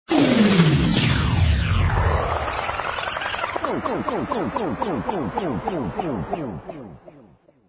rewinder fx